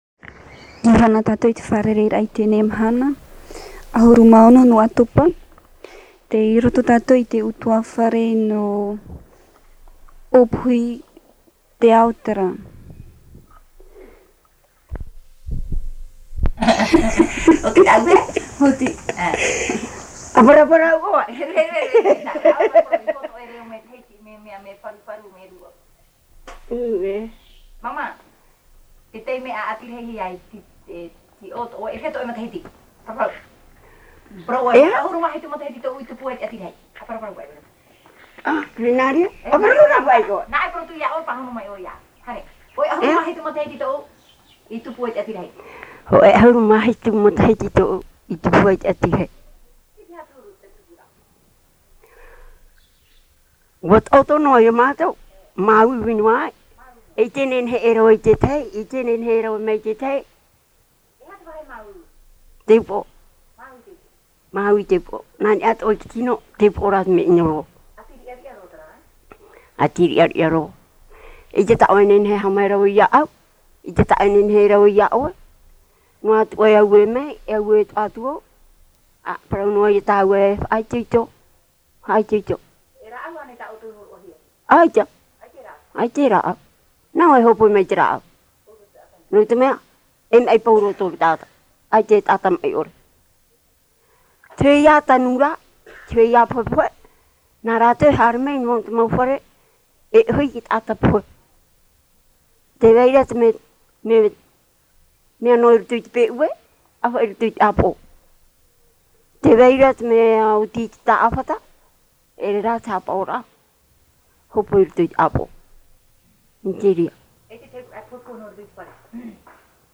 Descriptif de l'interview
Papa mātāmua / Support original : Cassette audio